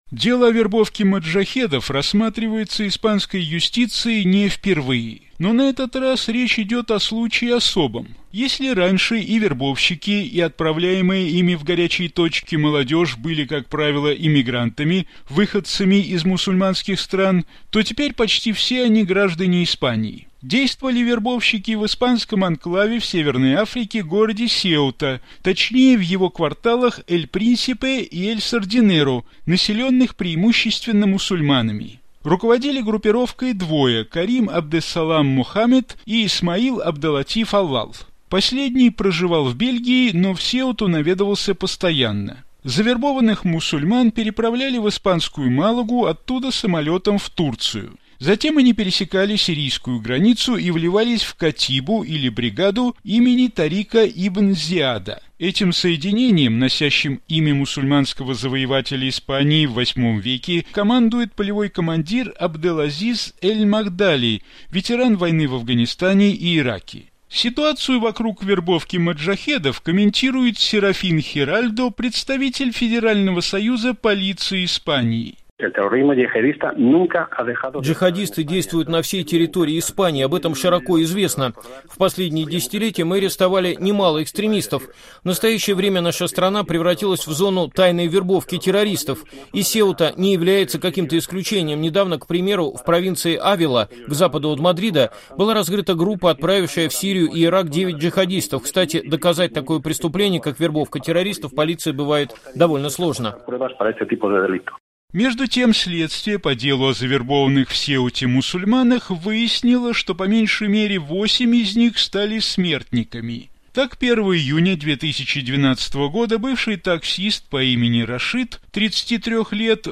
Вот образец обращения в интернете на испанском языке одного из джихадистов. Запись была сделана в Сирии.